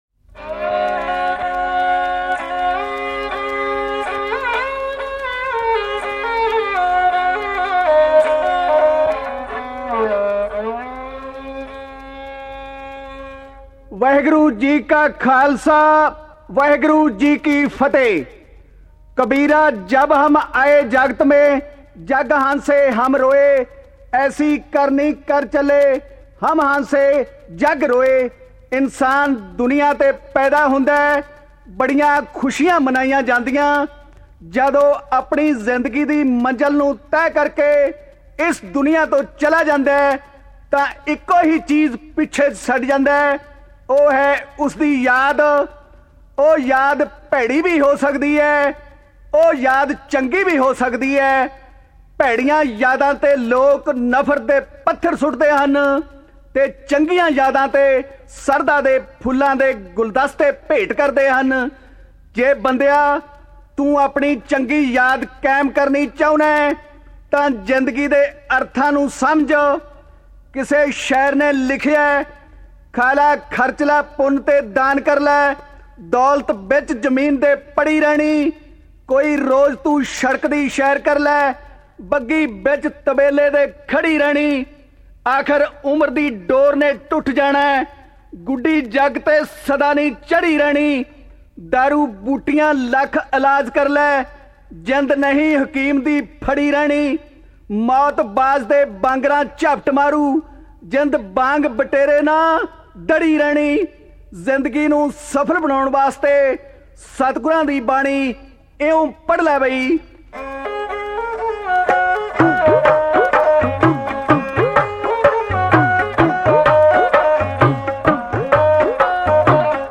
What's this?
Genre: Kavishri